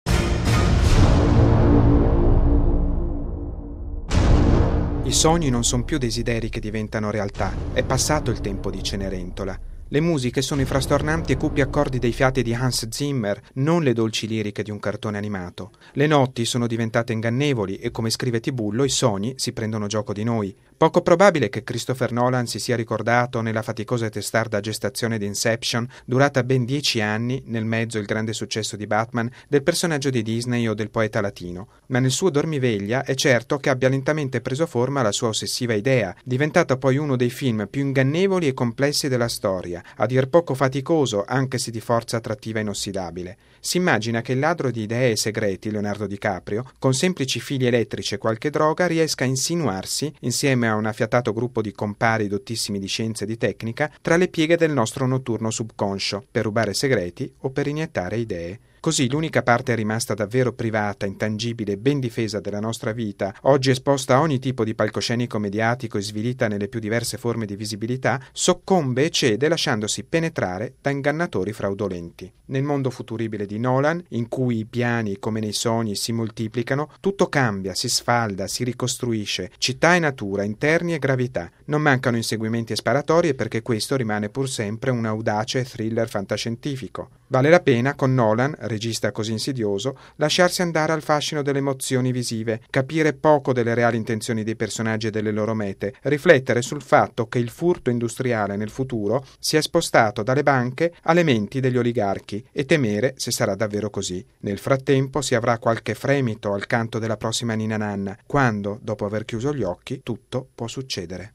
Ieri è uscito sugli schermi italiani, con l’incognita del pubblico, che può amare o odiare una storia faticosa e affascinante, in cui il protagonista entra nei sogni altrui per rubare segreti o innestare idee. Il servizio